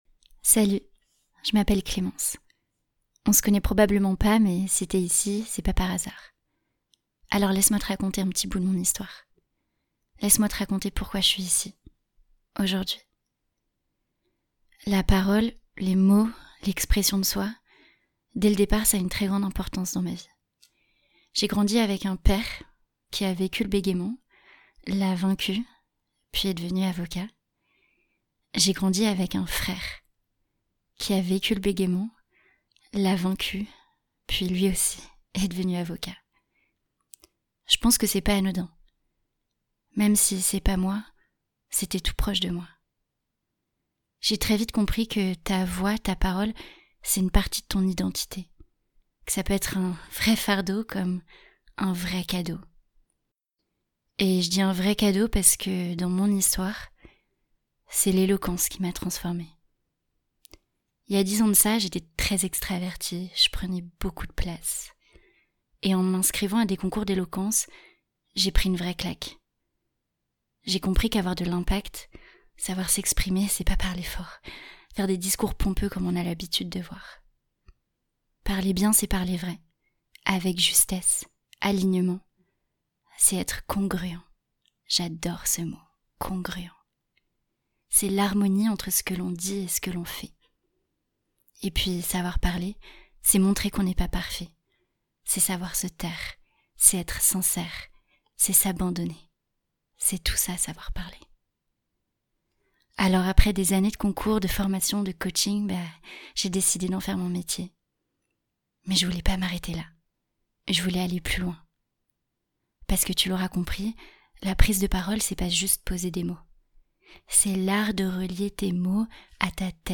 Derrière Aurora, il y a mon histoire : mes doutes, mes silences, mes propres blocages… et surtout le chemin qui m’a permis de trouver ma voix. Dans cet audio, je te raconte pourquoi j’ai choisi d’accompagner celles et ceux qui veulent, eux aussi, oser prendre la parole.